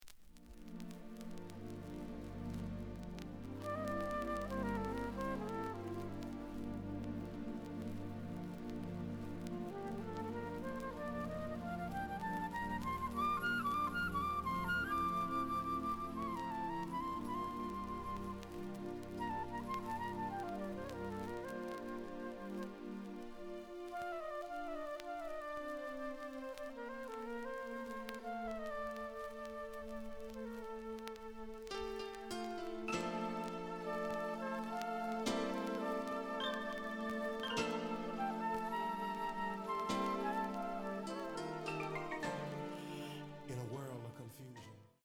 試聴は実際のレコードから録音しています。
●Genre: Hip Hop / R&B